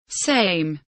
same kelimesinin anlamı, resimli anlatımı ve sesli okunuşu